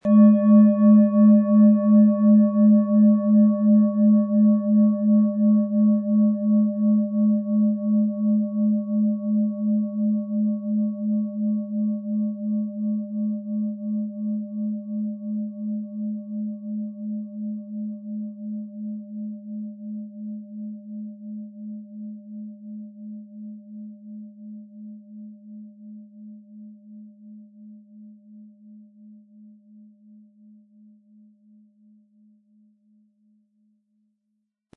• Mittlerer Ton: Saturn
PlanetentöneNeptun & Saturn
SchalenformOrissa
MaterialBronze